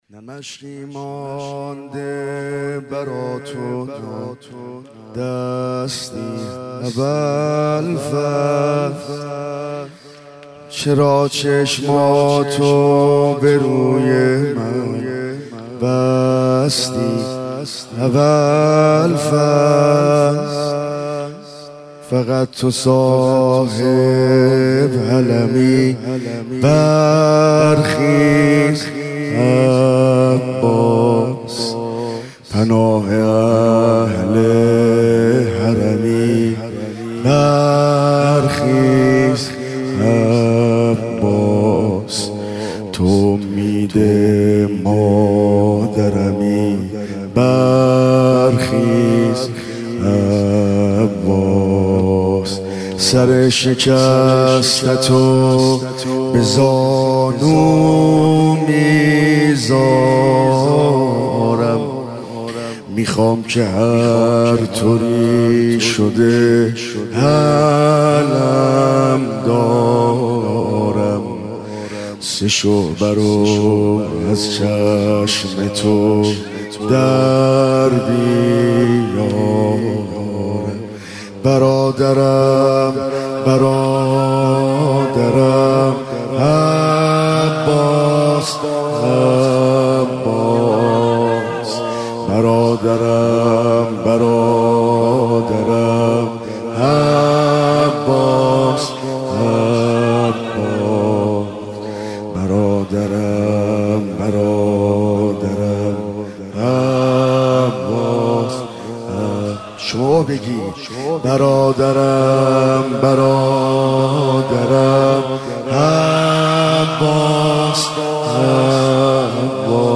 مداحی دهه محرم ۹۸